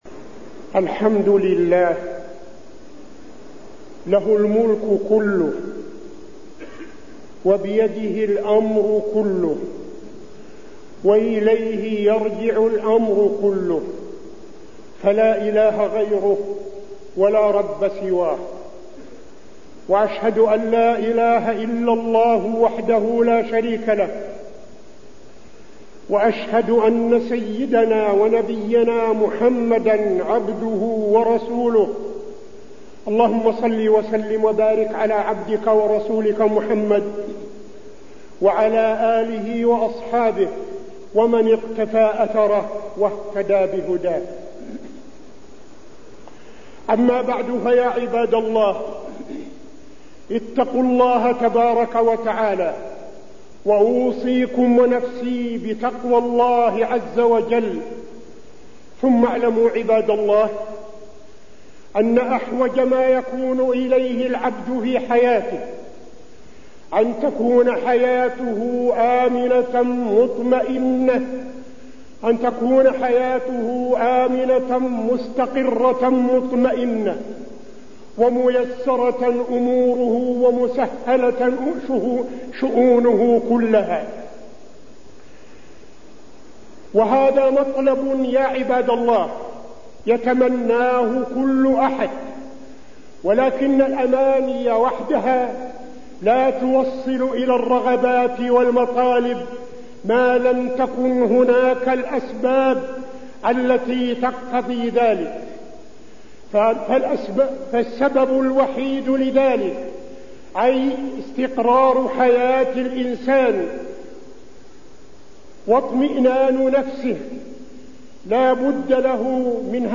تاريخ النشر ٢١ صفر ١٤٠٧ هـ المكان: المسجد النبوي الشيخ: فضيلة الشيخ عبدالعزيز بن صالح فضيلة الشيخ عبدالعزيز بن صالح التوبة